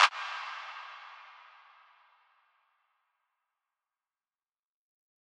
TS - CLAP (12).wav